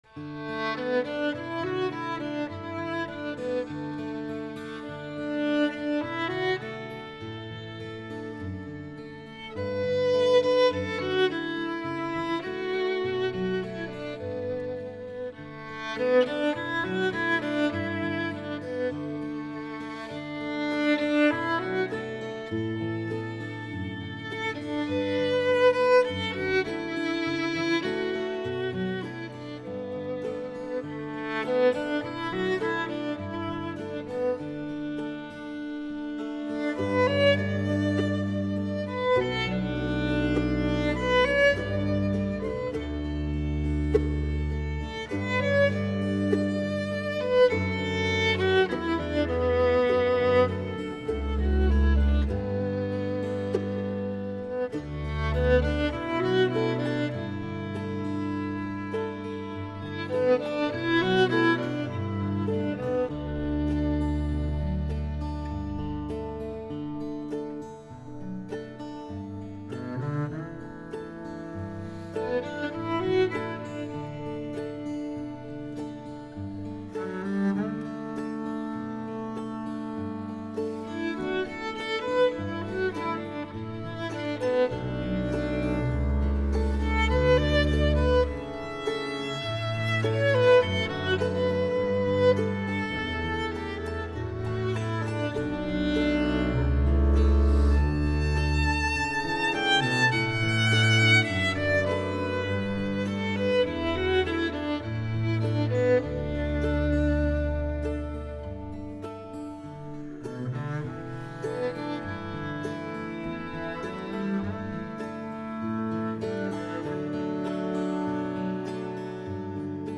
小提琴
低音提琴
曼陀铃
吉他